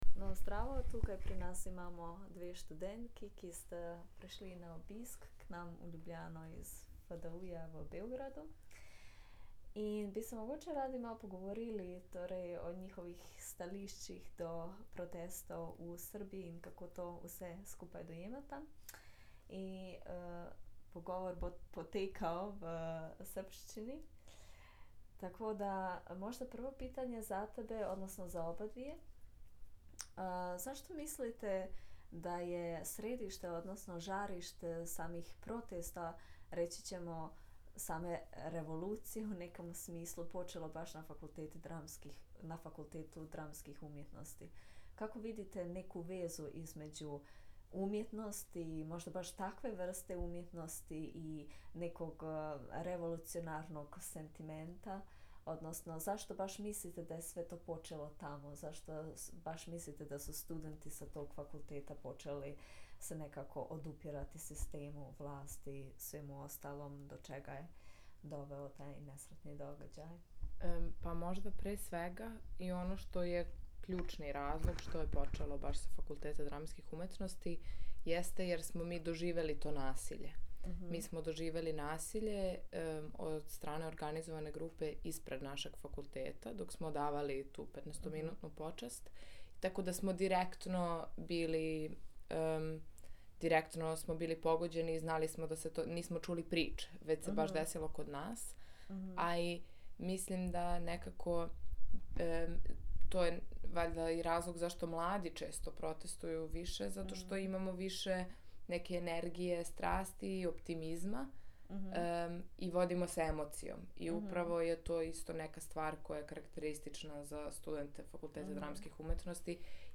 Recenziji in intervju